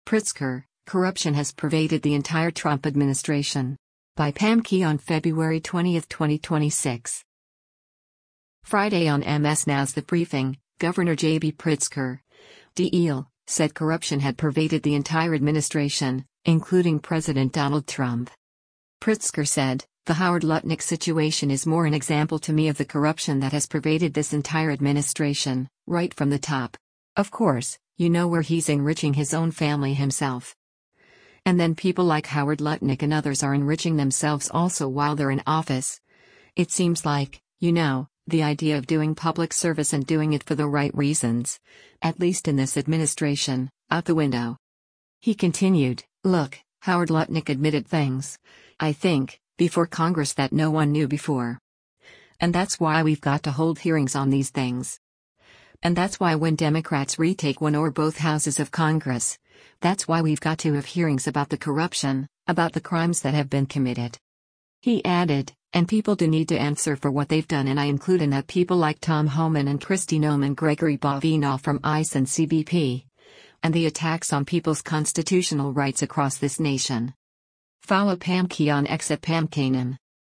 Friday on MS NOW’s “The Briefing,” Gov. JB Pritzker (D-IL) said corruption had pervaded the entire administration, including President Donald Trump.